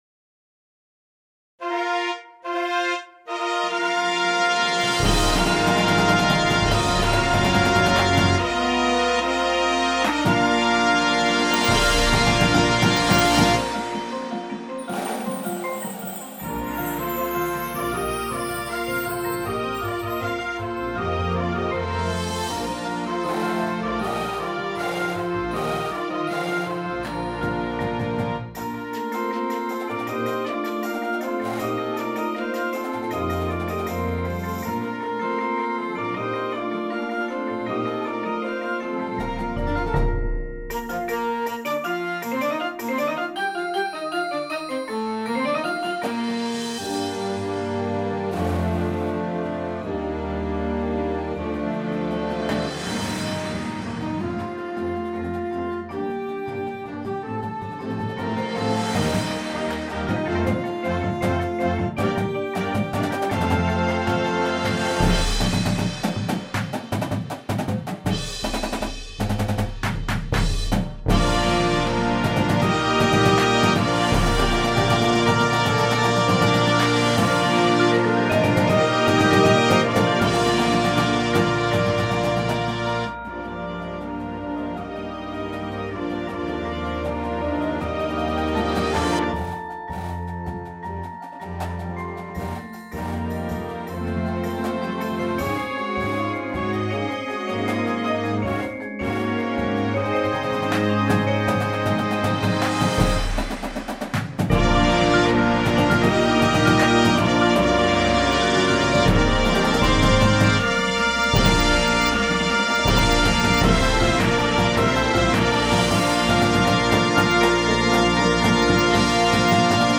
Instrumentation:
• Flute
• Clarinet 1, 2
• Alto Sax 1, 2
• Trumpet 1
• Horn in F
• Trombone 1, 2
• Tuba
• Snare Drum
• Synthesizer
• Marimba – Two parts
• Vibraphone – Two parts
• Glockenspiel